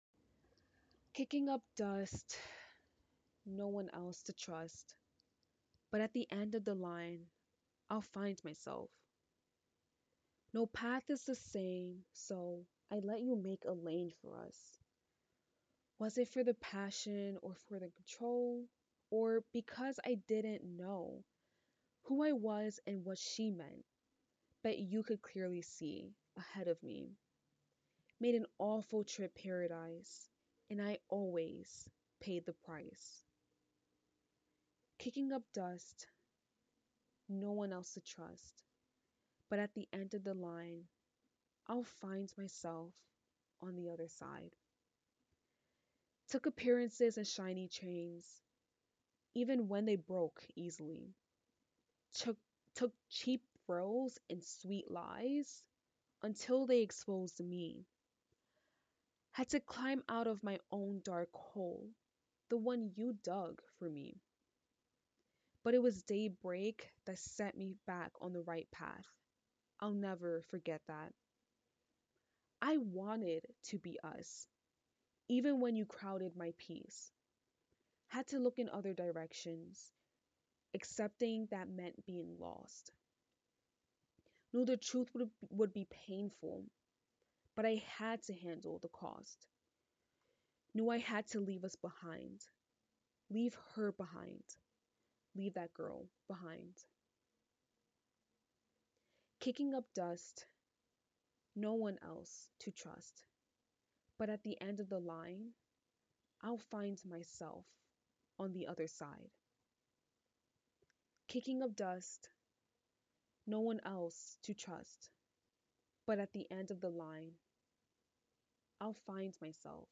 spoke word (demo)